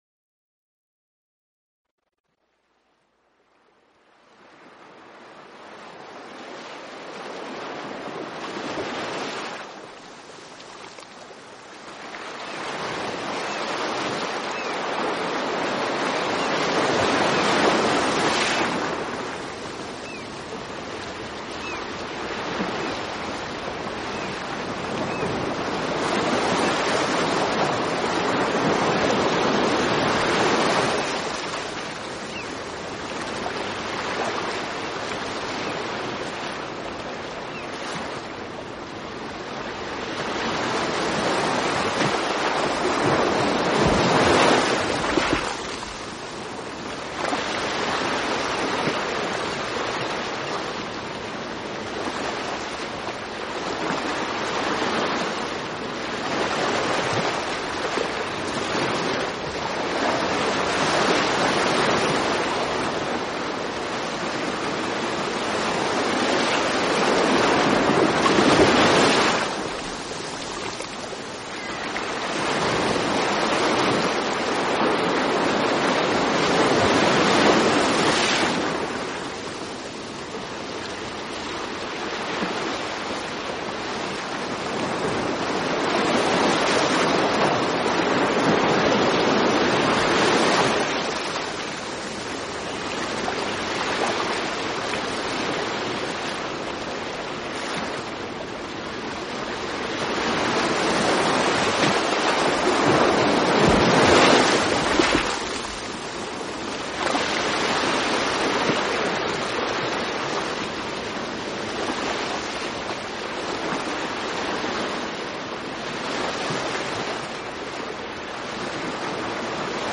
Gentle_Ocean.mp3